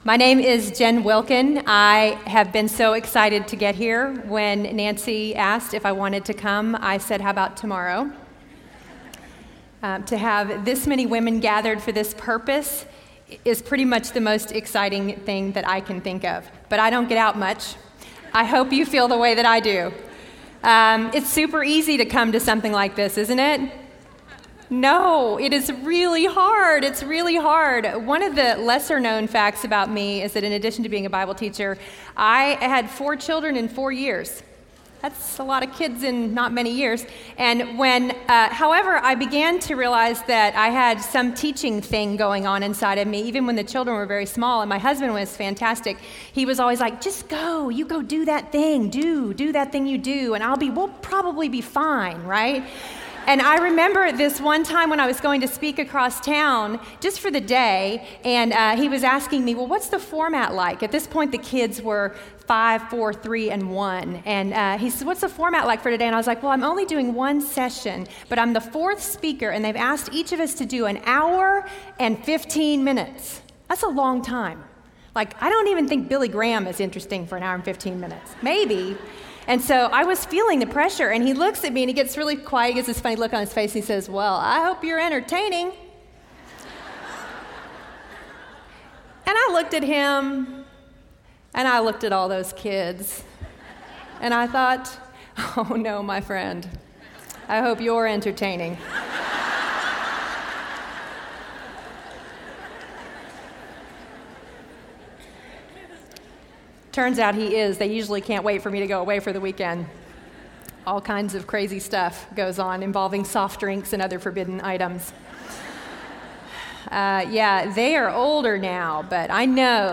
Teaching the Word in Fear and Fearlessness | Revive '15 | Events | Revive Our Hearts